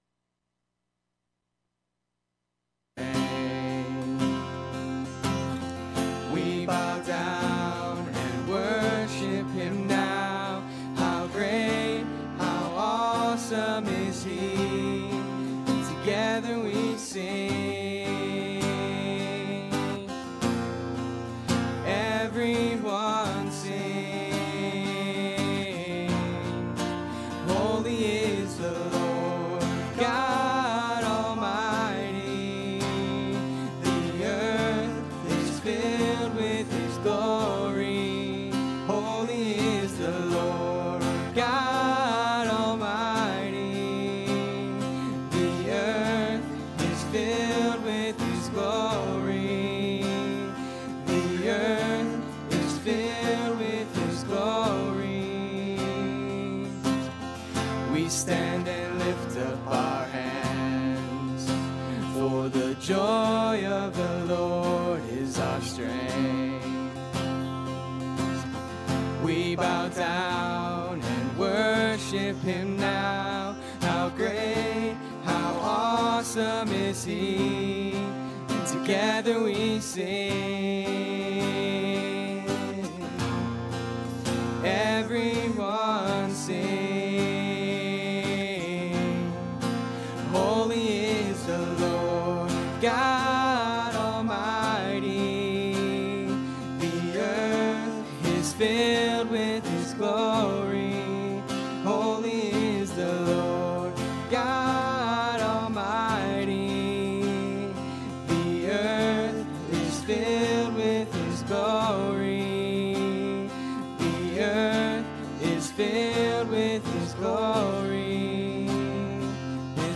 Passage: Mark 12:35-44 Service Type: Sunday Morning